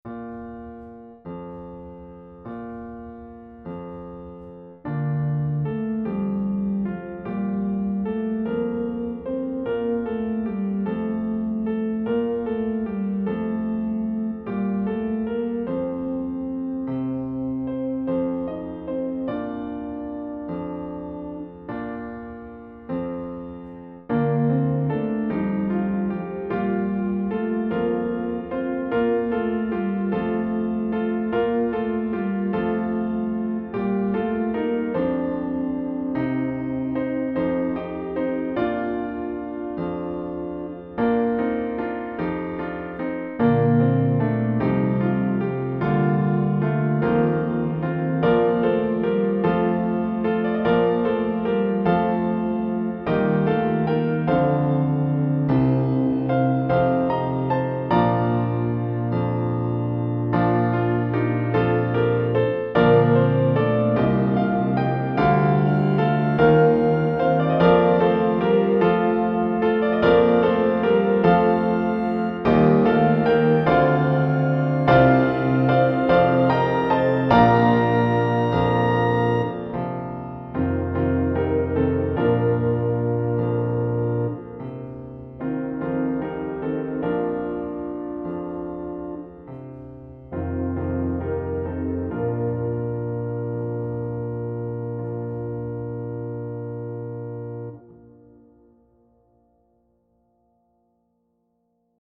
SATB with piano accompaniment.
(Vocal parts are played on Organ)